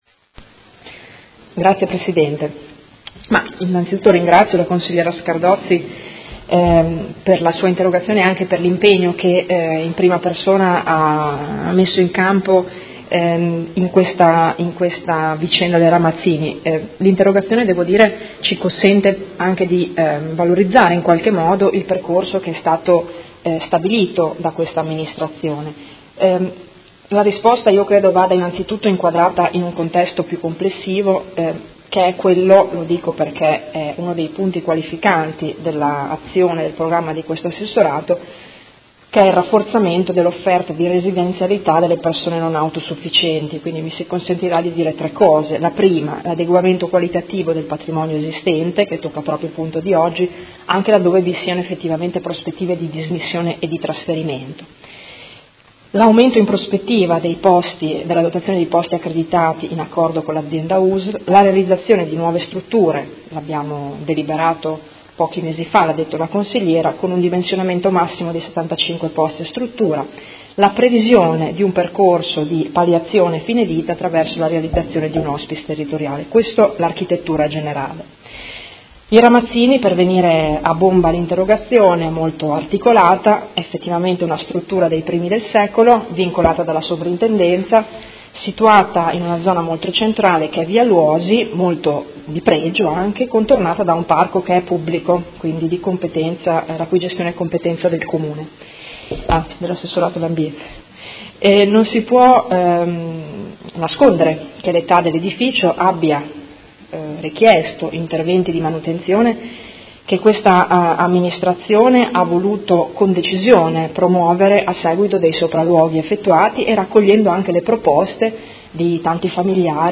Seduta del 31/05/2018 Risponde a Interrogazione del Gruppo M5S avente per oggetto: CRA Ramazzini